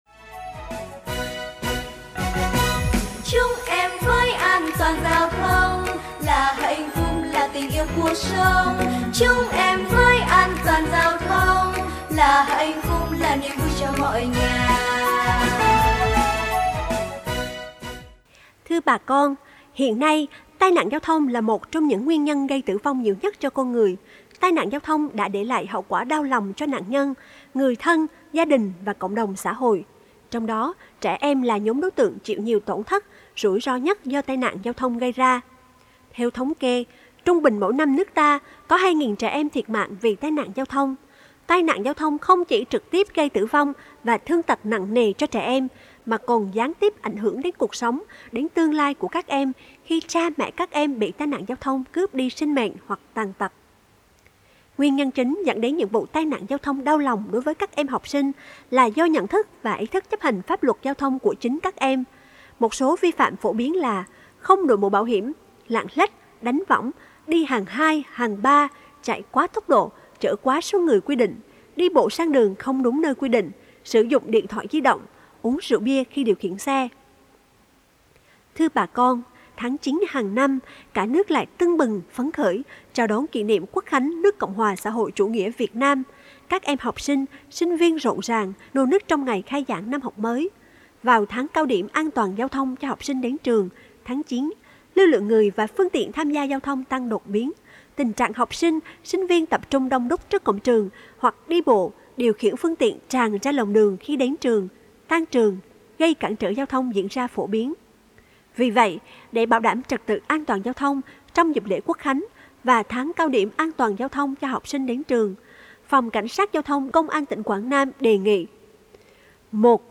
Tuyên truyền phát thanh về An toàn giao thông